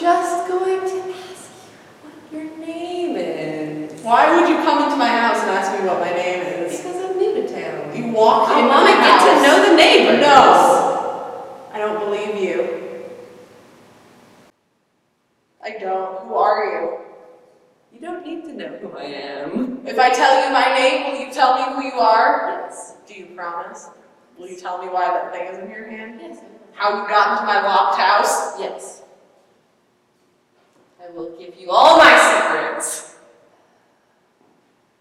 Echo/reverb-like sound.
We had some bad acoustics in the recording space, and I didn’t realize it until way too late to re-record.
It also sounds crackly and overloaded which is number two.
When you listen to the proof clip I posted, the silent portion between the words suddenly goes quiet.